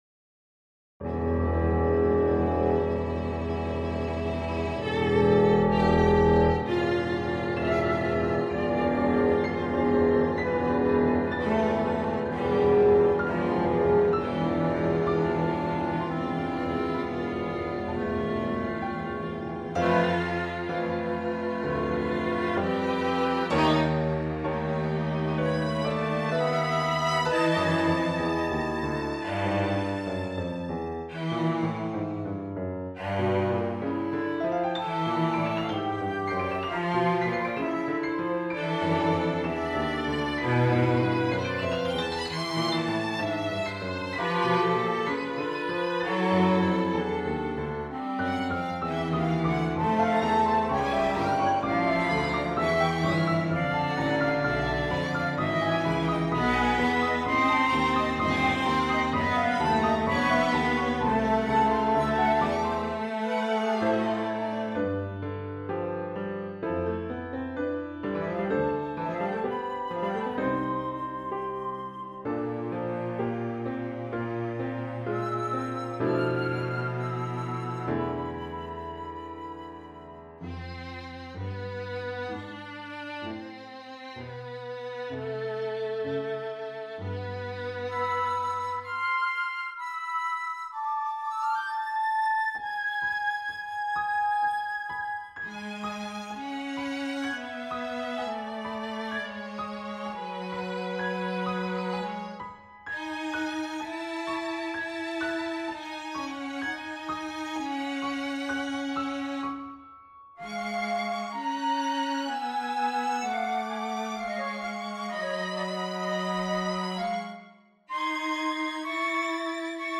for Pierrot Lunaire ensemble